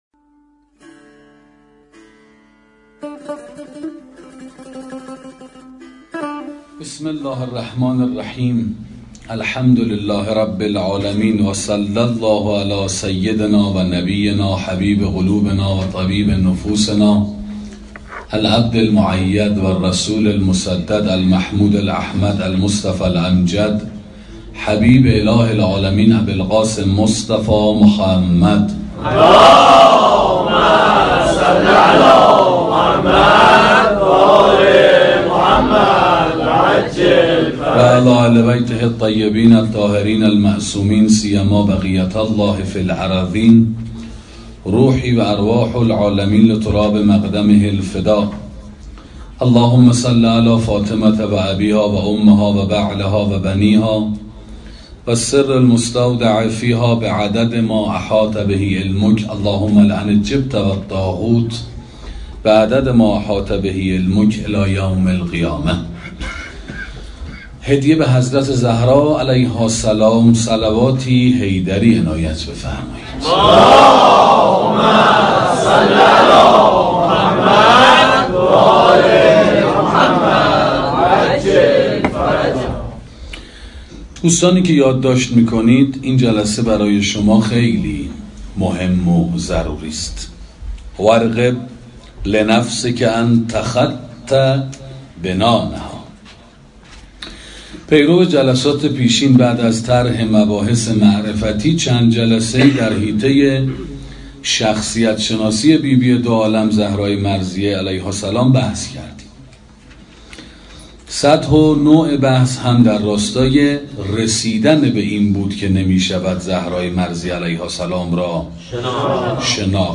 سخنرانی شناخت خصائص حضرت زهرا (س) 2